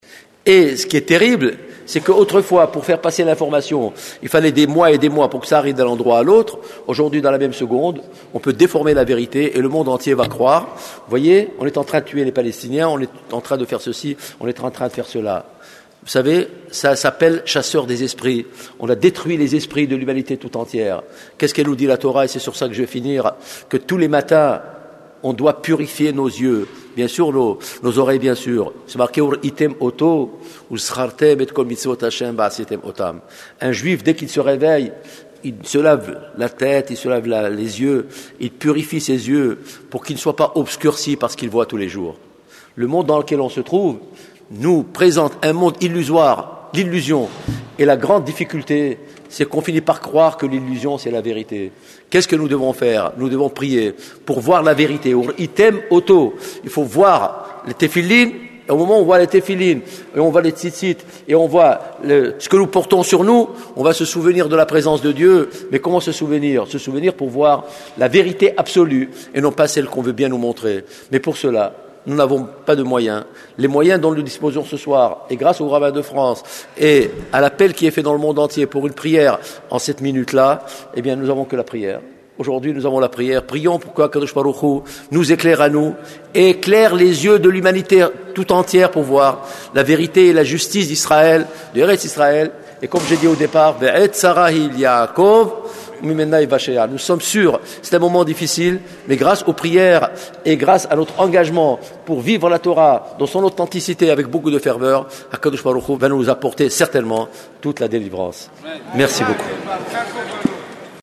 02:13:36 Grand rassemblement de sursaut communautaire tenu le 27 novembre 2000 à la grande synagogue de la rue de la Victoire à Paris.